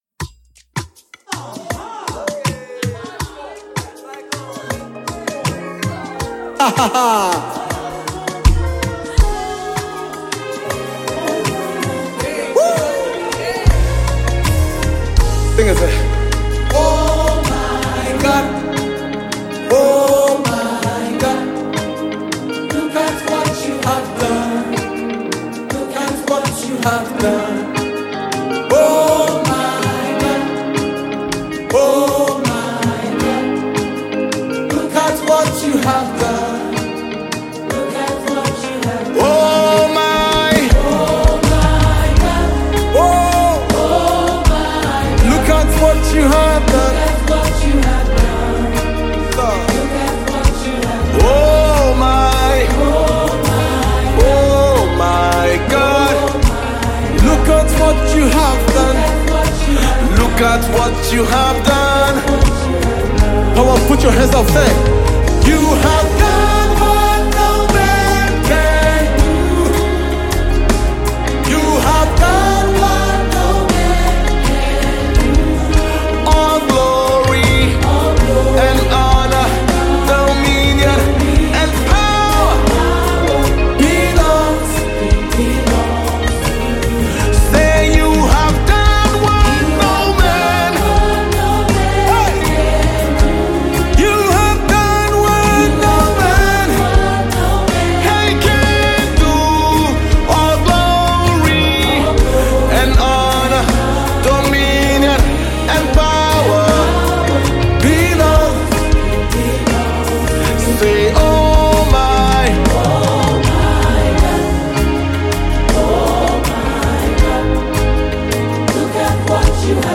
A High-Energy Anthem of Awe and Worship
gospel banger